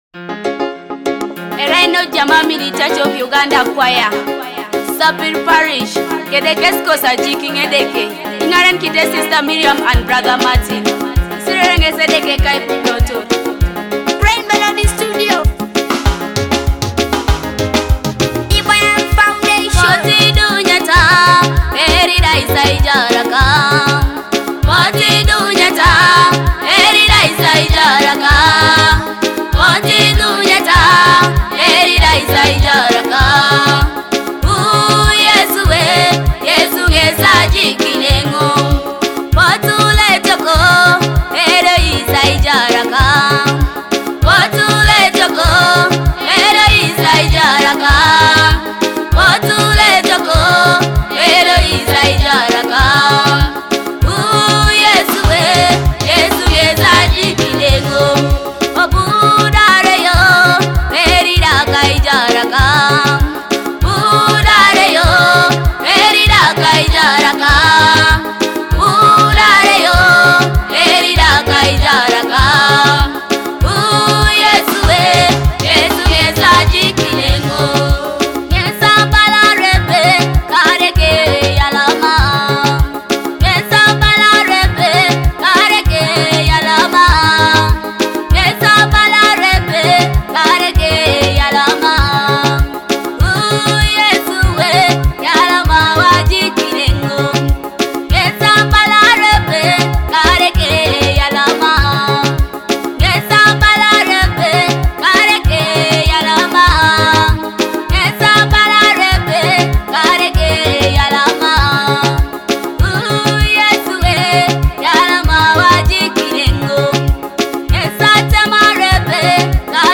gospel track